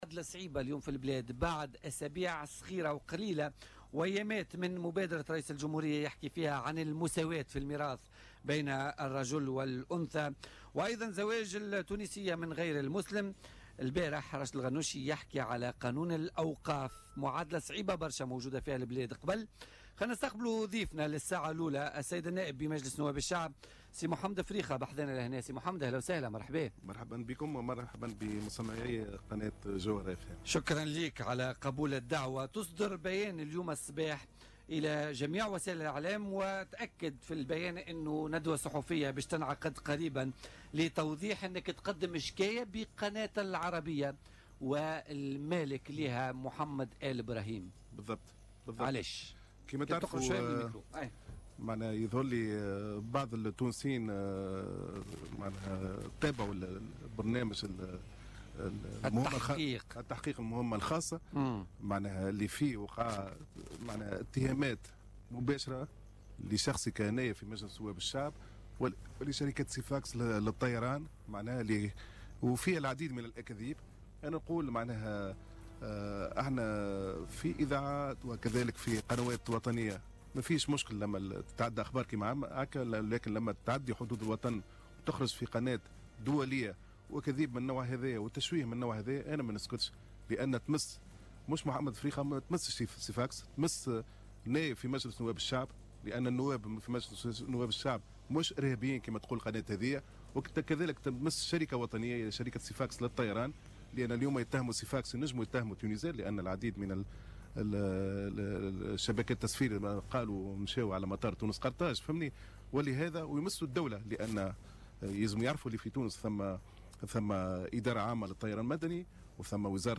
أكد النائب محمد الفريخة وباعث شركة سيفاكس للطيران ضيف بولتيكا اليوم الثلاثاء 29 أوت 2017 أنه يعتزم تقديم قضية بقناة العربية ومالكها بعد التحقيق الذي تم بثه السبت الماضي والاتهامات الكاذبة التي طالته .